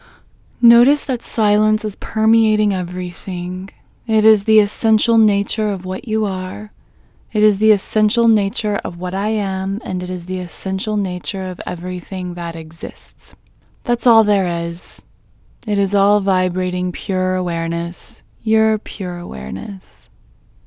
WHOLENESS English Female 19